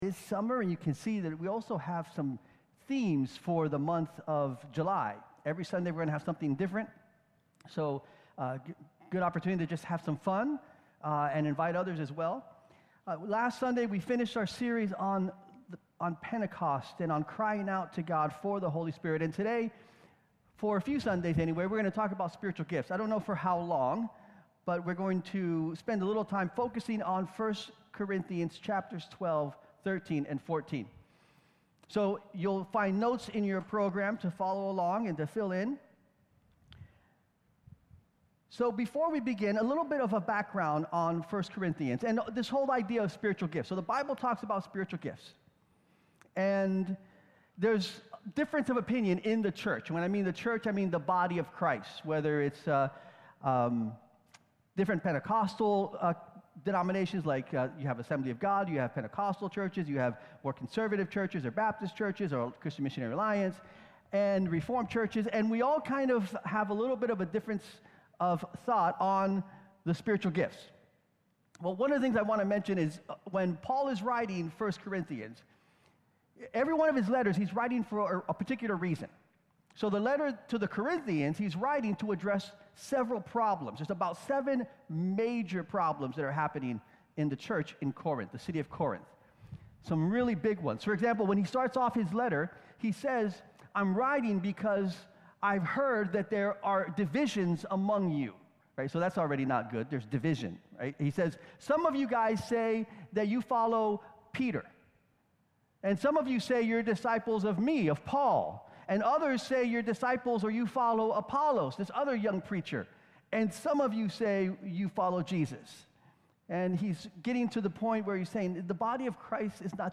Sermon Archives | Syracuse Alliance Church